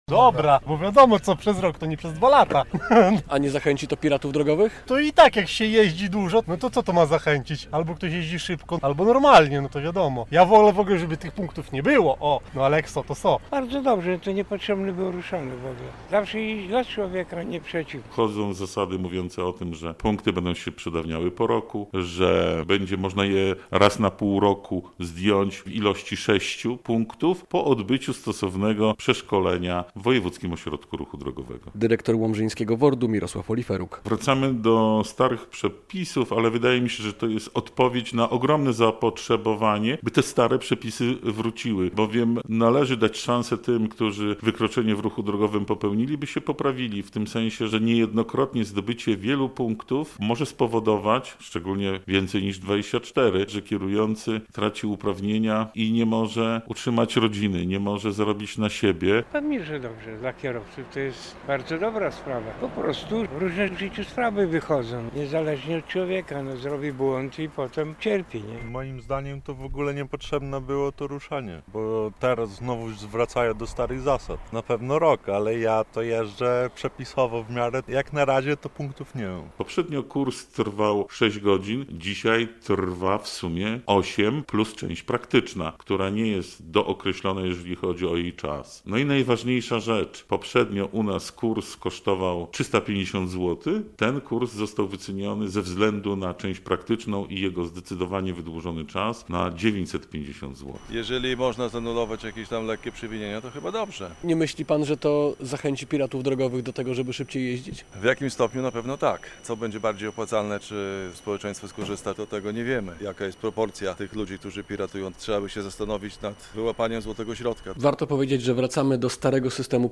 Różnic jest dużo, ale kierowcy z Łomży, z którymi rozmawiał nasz reporter, i tak są zadowoleni.